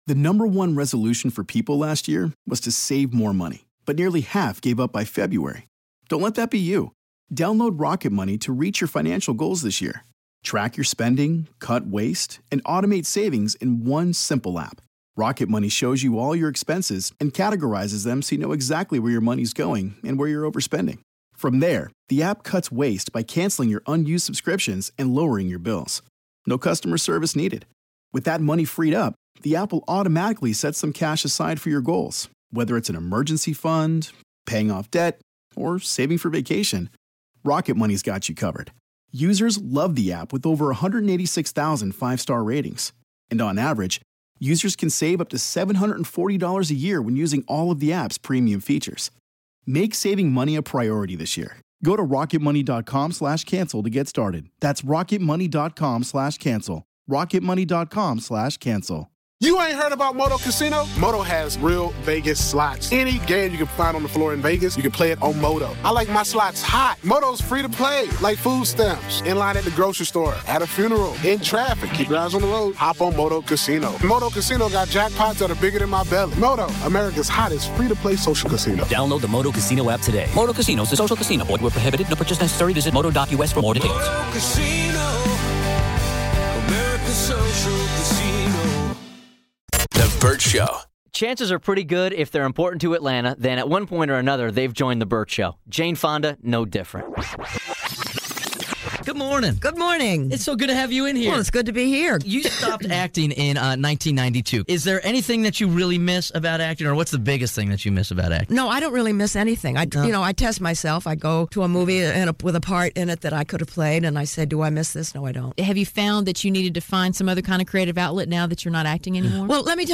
Vault: Interview With Jane Fonda